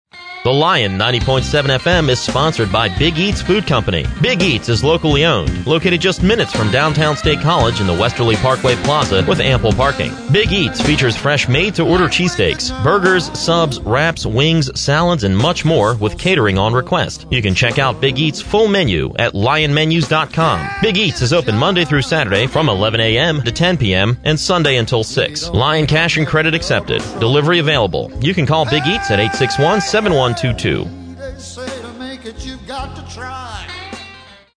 An archive of local sponsorship announcements and underwriting messages that have aired on The LION 90.7fm, produced in-house by undergraduates and alumni and faculty production advisers.